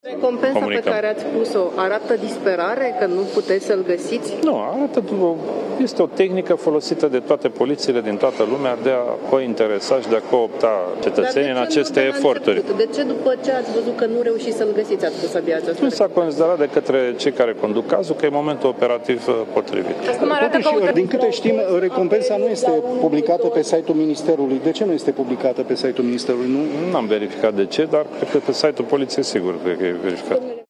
Cătălin Predoiu, ministrul de Interne: Este o tehnică folosită de polițiile din toată lumea